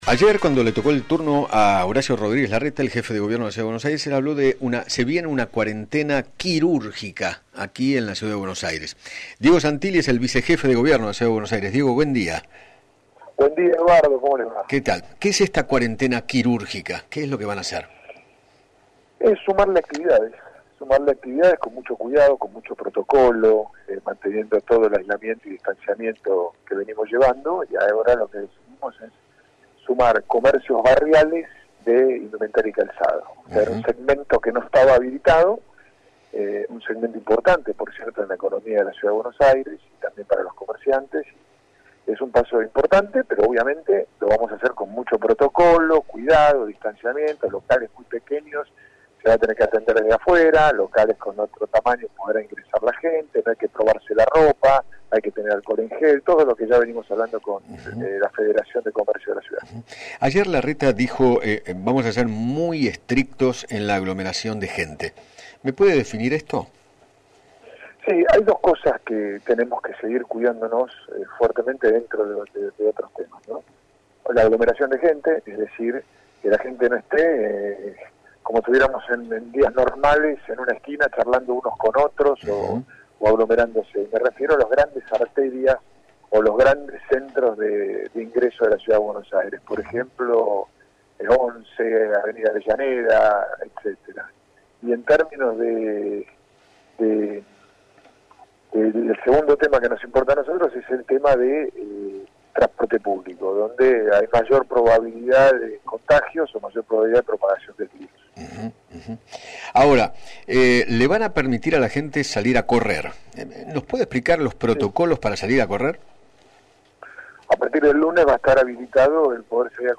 Diego Santilli, Vicejefe de Gobierno de la Ciudad de Buenos Aires, dialogó con Eduardo Feinmann sobre la expresión que utilizó Horacio Rodríguez Larreta para referirse a la nueva etapa que se extiende hasta el 28 de junio y explicó cuáles son las actividades que se habilitarán en los próximos días.